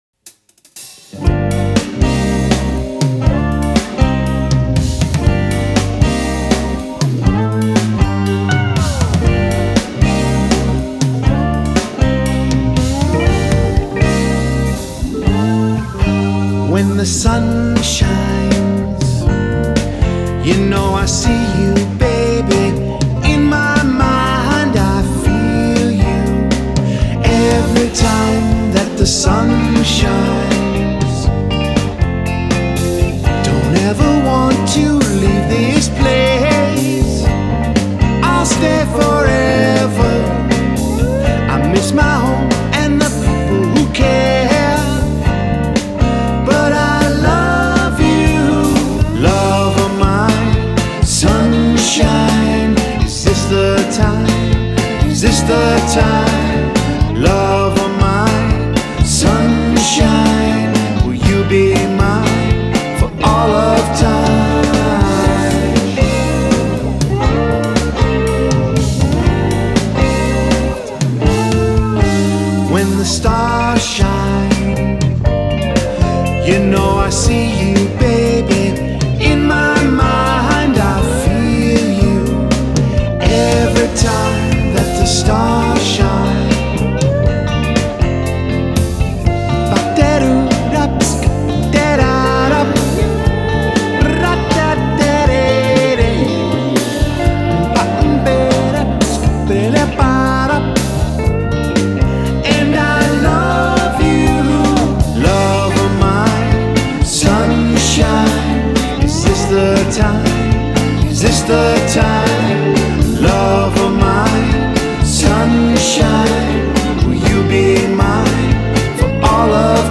Тип альбома: Студийный
Жанр: Blues-Rock